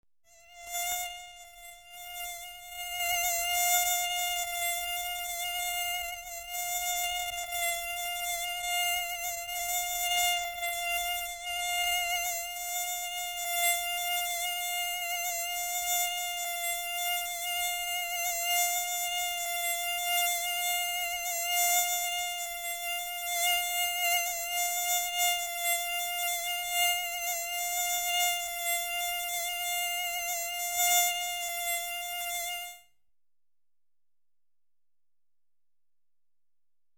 Звуки комара
Писк комара в тишине